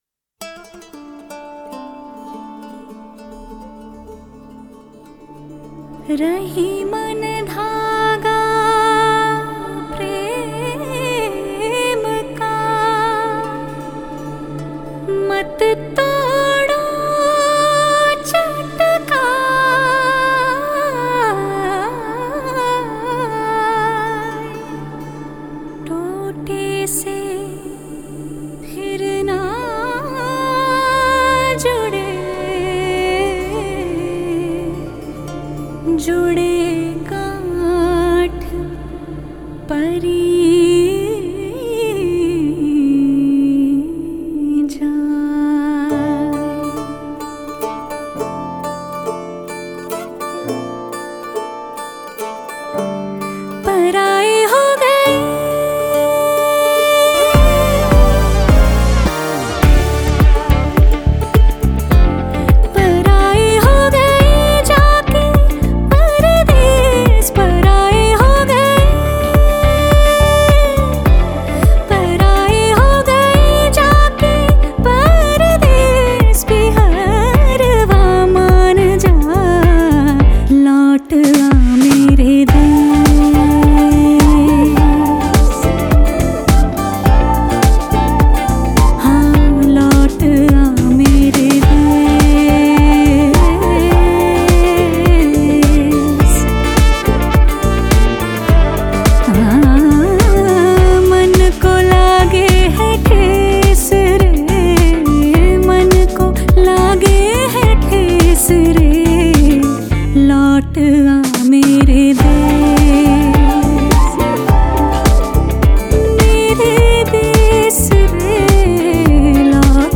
INDIPOP MP3 Songs
IndiPop Music Album